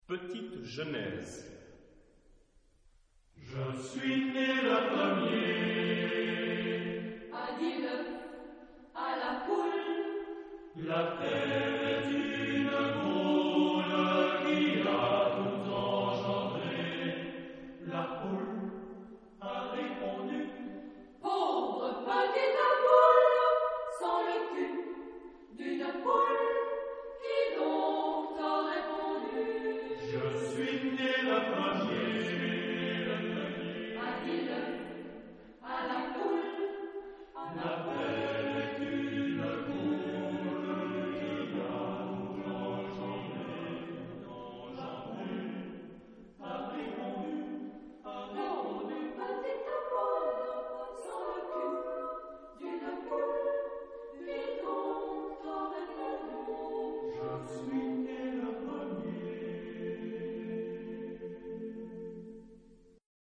Genre-Style-Forme : Suite chorale ; Canon ; Poème ; Profane
Type de choeur : SATB  (4 voix mixtes )